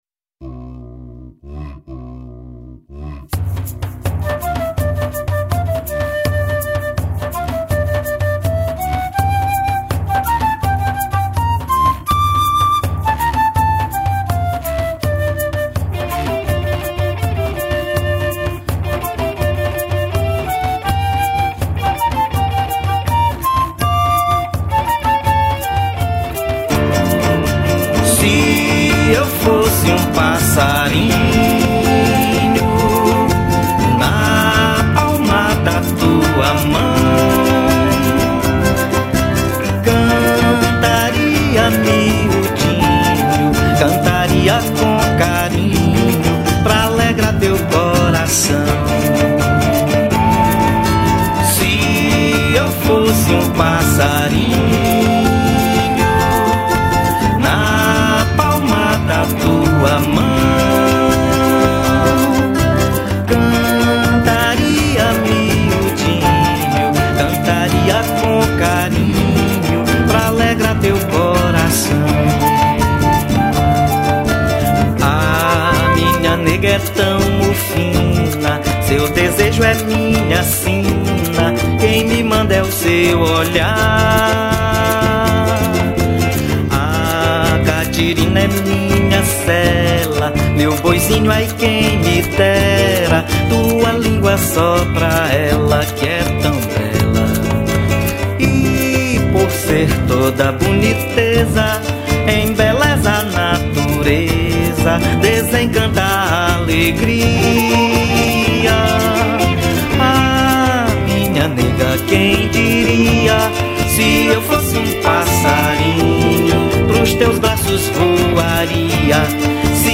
04:27:00   Boi Bumbá